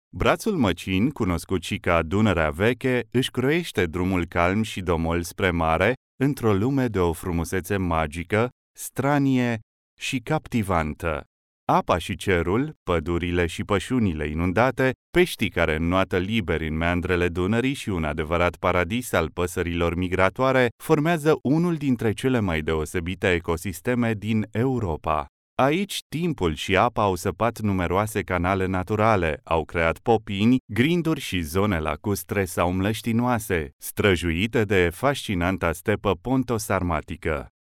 Documentaires
I am a male professional Romanian native voice talent since 1999.
Neumann U87 and TLM 103 microphones
BarytonBasseProfond